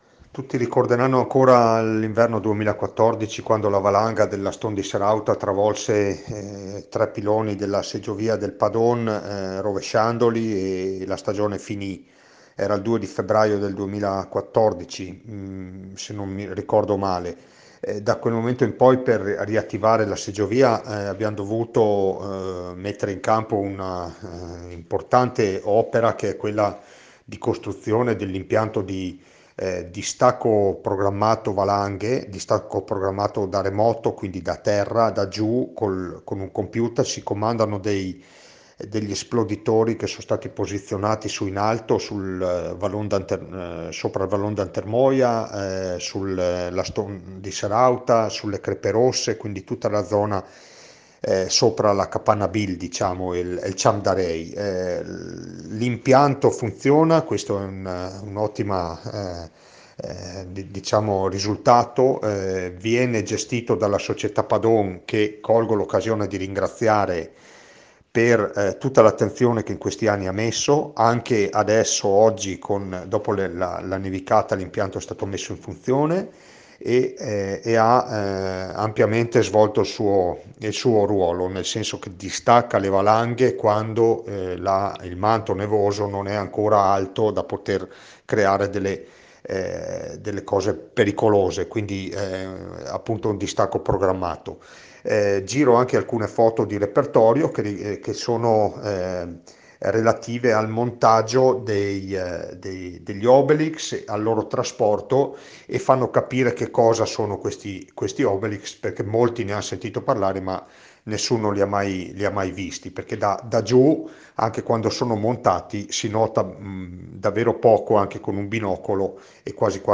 IL SINDACO DI ROCCA PIETORE ANDREA DE BERNARDIN